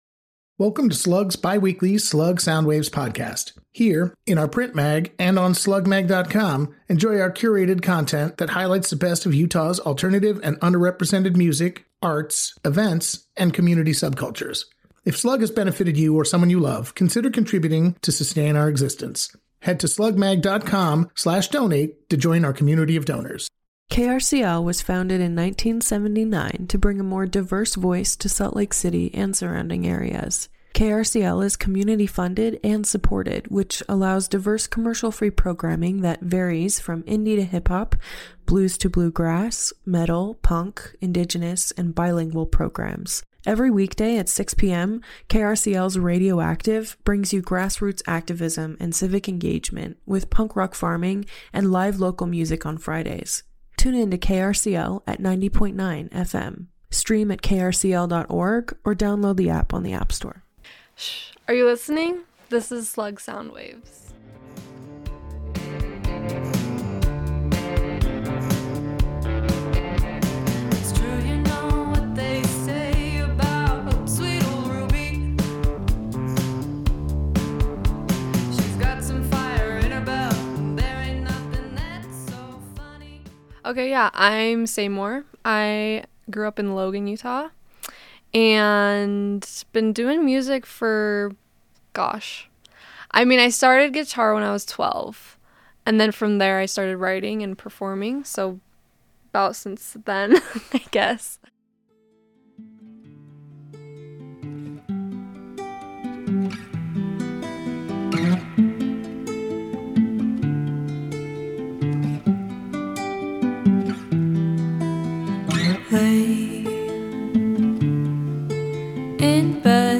indie and alternative sounds